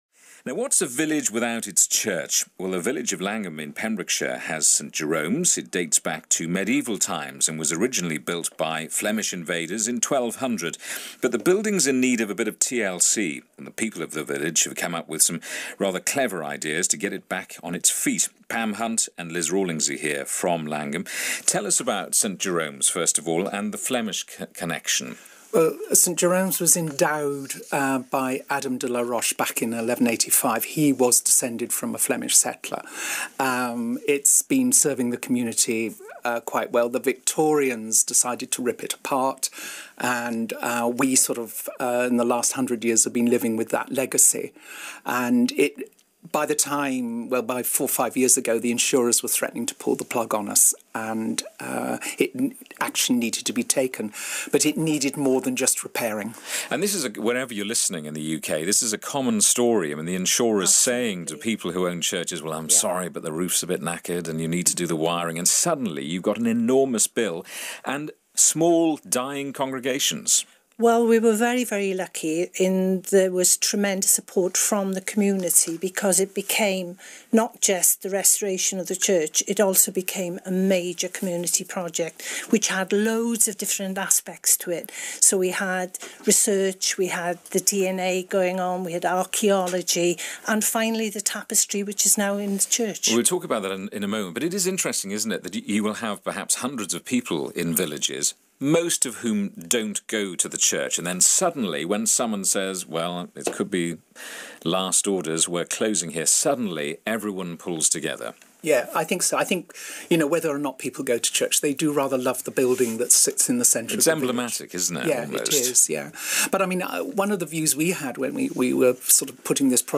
Jamie Owen Interviews